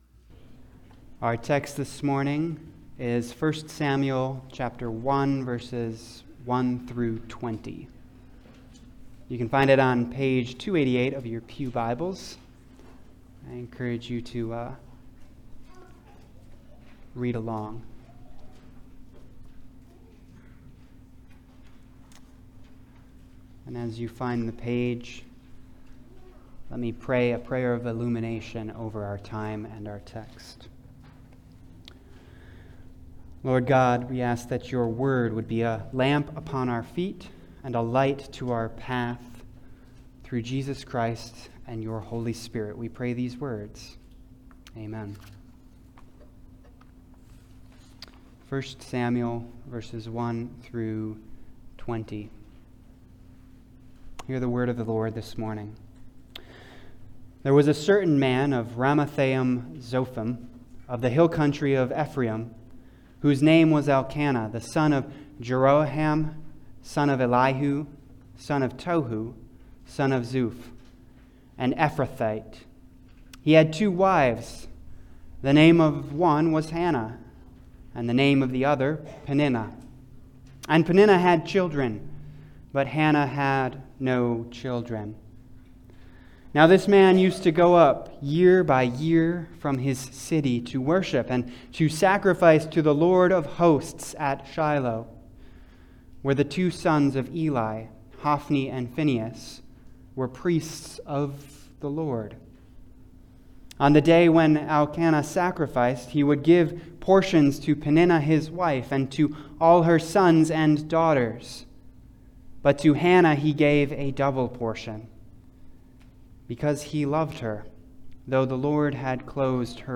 1 Samuel 1:1-26 Service Type: Sunday Service « Created Images and Renewed Imitators What’s Love Got to do With It?